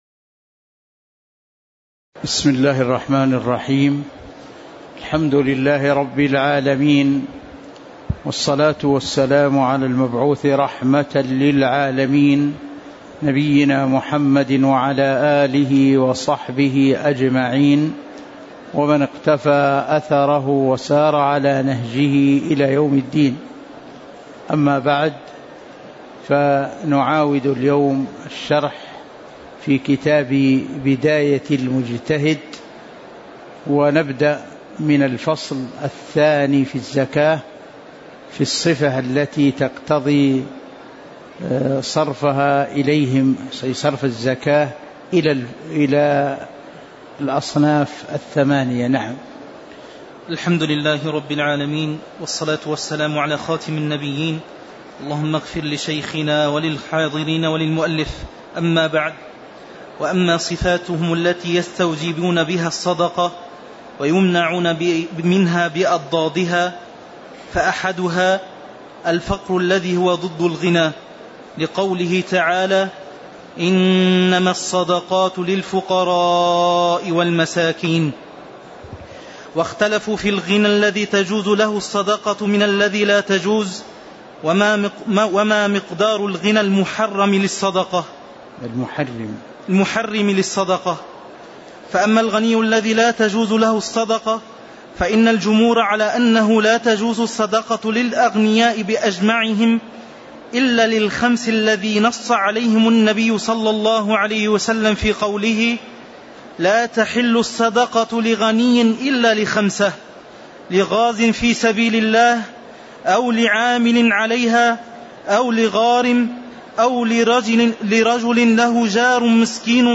تاريخ النشر ٢١ صفر ١٤٤٦ هـ المكان: المسجد النبوي الشيخ